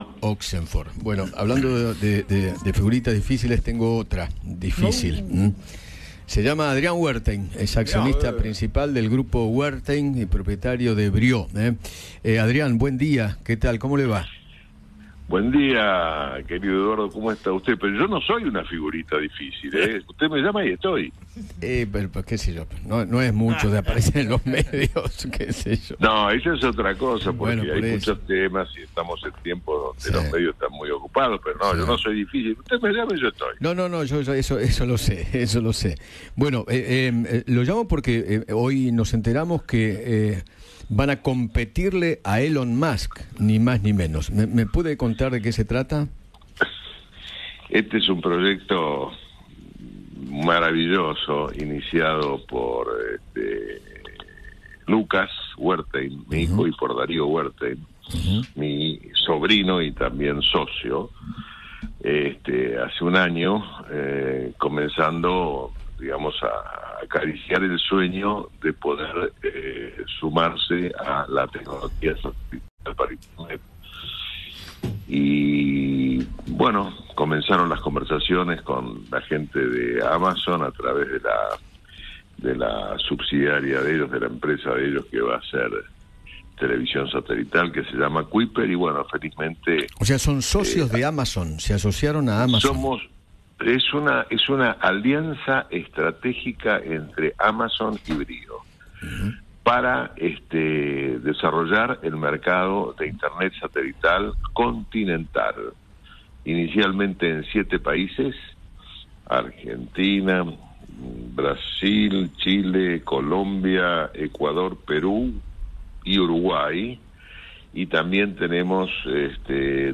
habló con Eduardo Feinmann sobre la alianza con Amazon para ofrecer conectividad a Internet rápida y asequible en 7 países de Sudamérica.